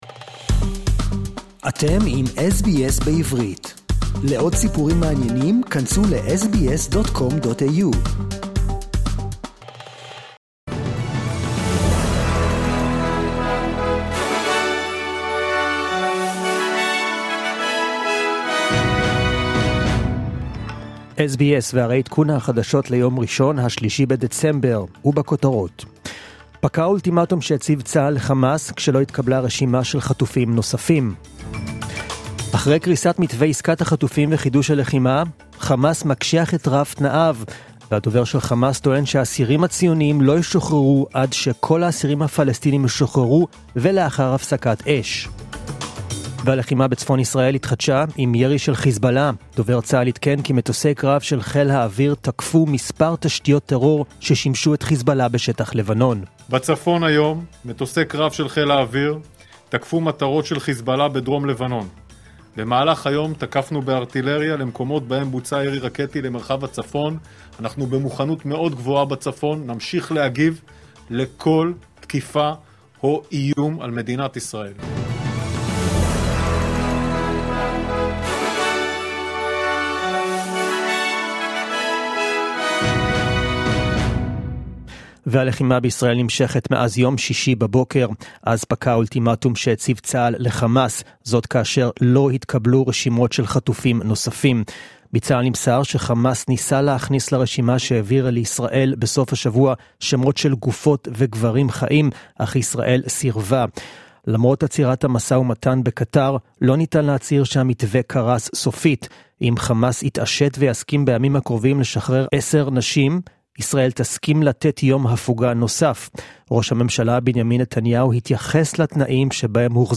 The News in Hebrew (3.12.23)
The latest news and updates, as heard on the SBS Hebrew program